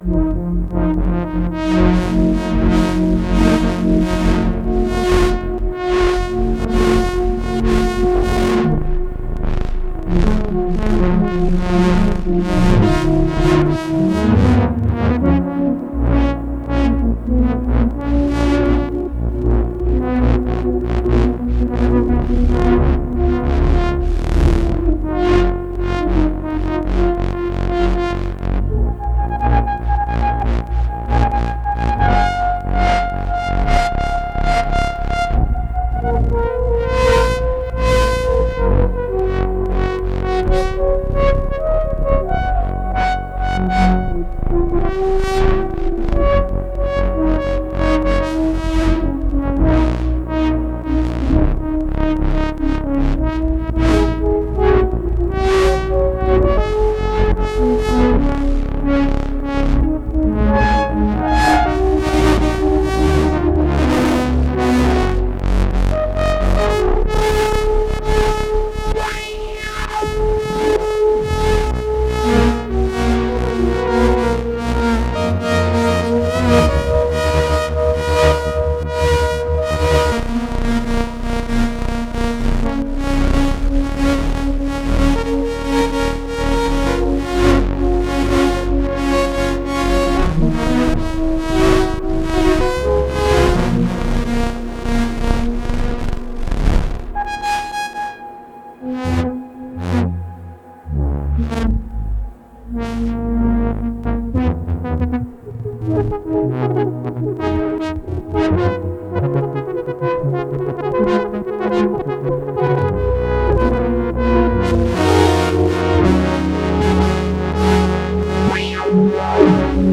Sequential Take 5 five voice poly
came up with a patch i really liked today. some fm, looping envelopes, audio rate modulation of things like filter drive & vintage, a lot of mod slot modulation, filter out to cutoff, that sort of thing
that’s wild and unruly. nice.